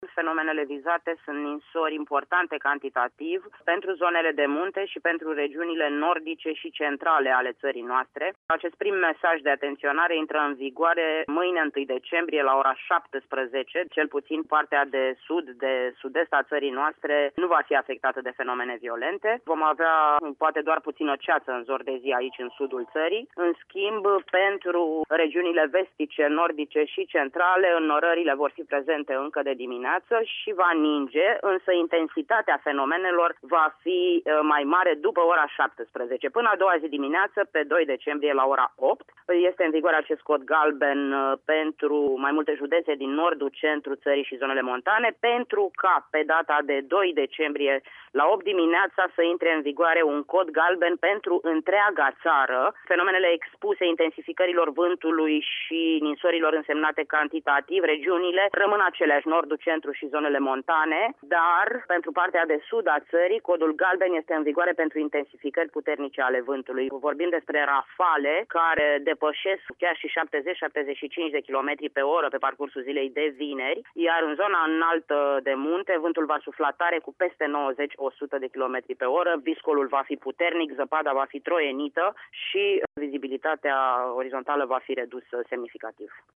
Vineri dimineaţă, avertizarea se extinde pentru toată ţara. Meteorologul de serviciu